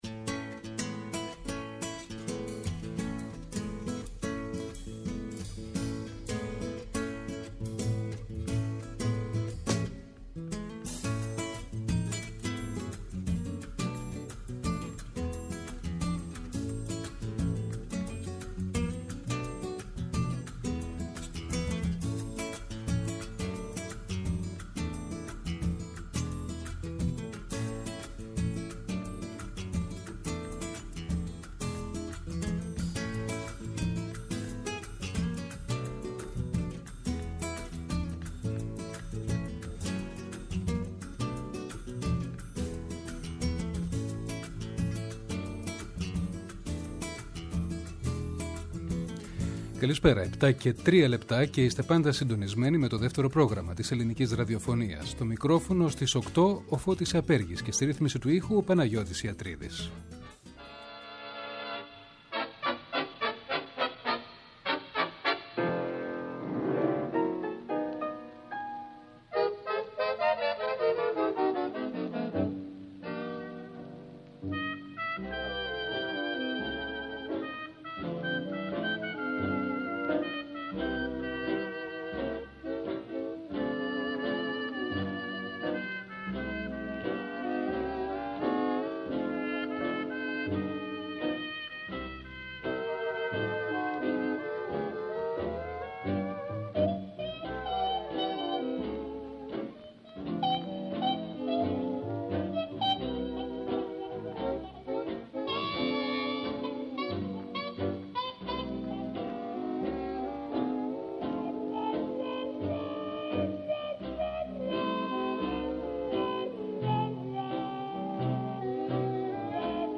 Καθώς ακούστηκαν η νέα μουσική και τα τραγούδια που έγραψε η Κατερίνα Πολέμη για την παράσταση, ο Δημ.Τάρλοου, μίλησε επίσης για τον ίδιο τον Μ.Καραγάτση, τον παππού του, και για το τόλμημα της σκιαγράφησης τής άγνωστης πλευράς ενός από τους σημαντικότερους Ελληνες λογοτέχνες.
ΔΕΥΤΕΡΟ ΠΡΟΓΡΑΜΜΑ Η Καταλληλη Ωρα ΘΕΑΤΡΟ Θέατρο Συνεντεύξεις